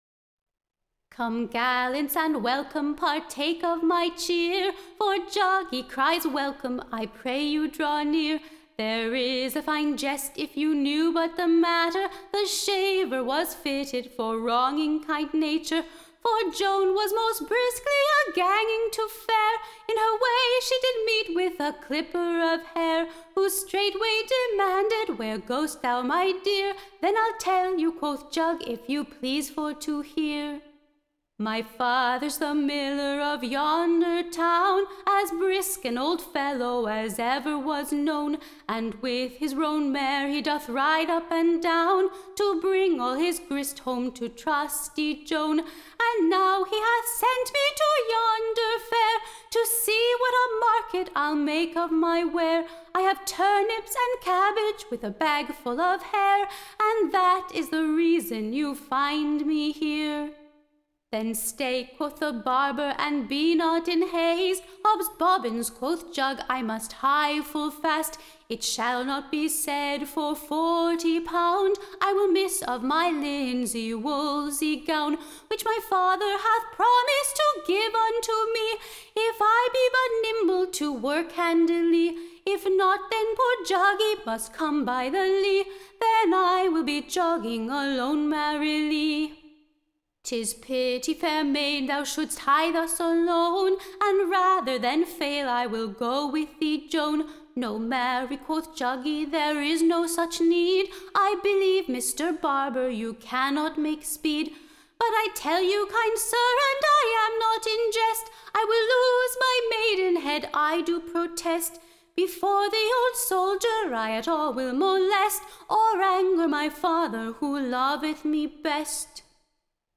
Recording Information Ballad Title A Groats-worth of Mirth for a PENNY, / OR, / Will the Barber well Fitted for Cheating the Millers jolly Daughter Ioan of a Fat Pig.
Tune Imprint To the Tune of, The Country Farmer.